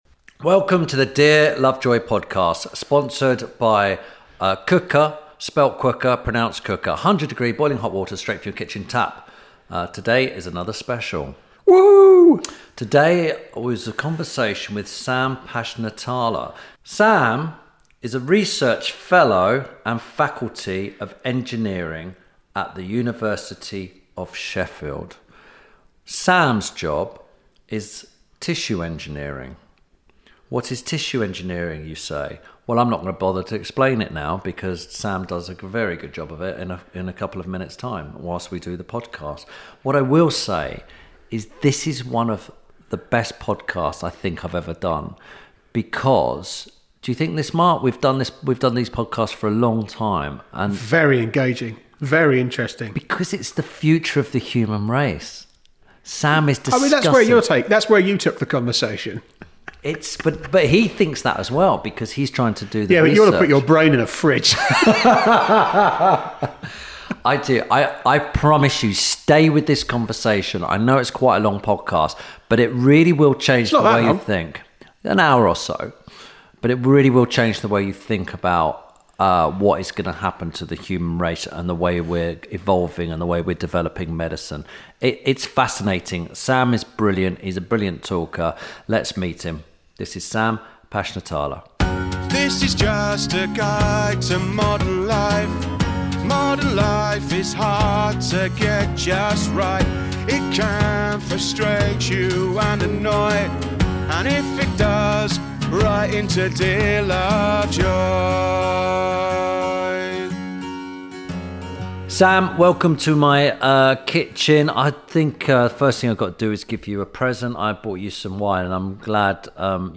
– INTERVIEW SPECIAL